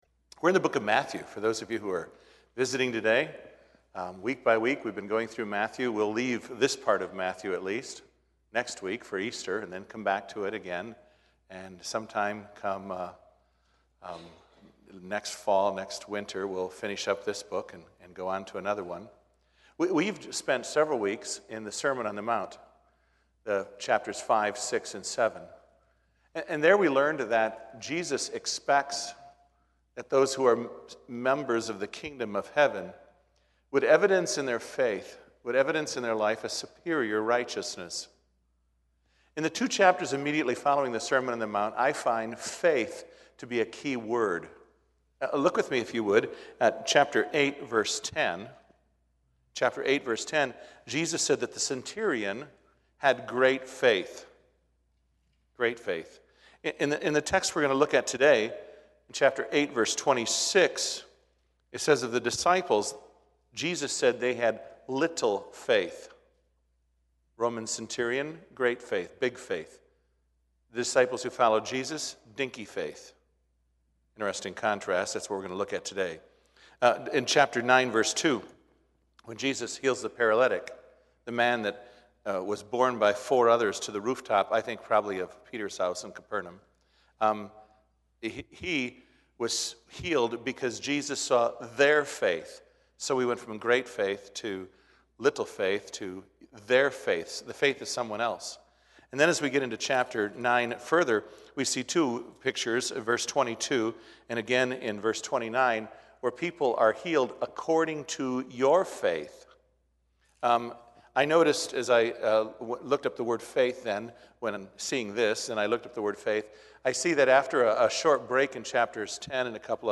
sermon audio 24.